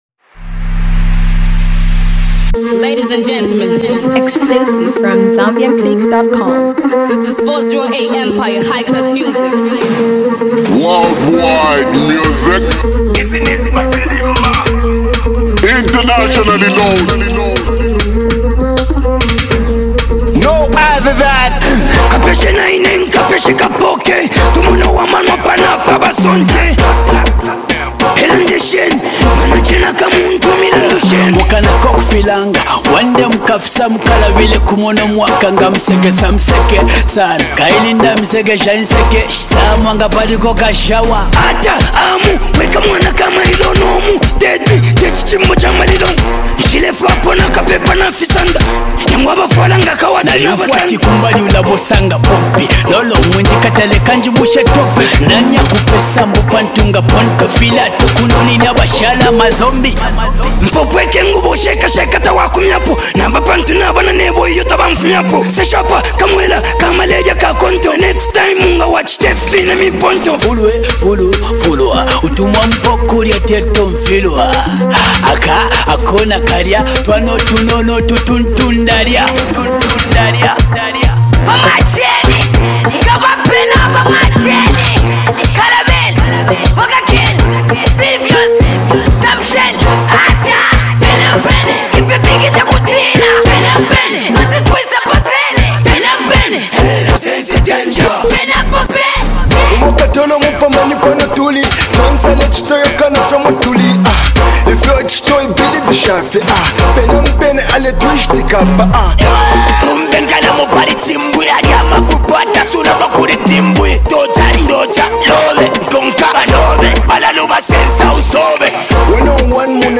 street jam song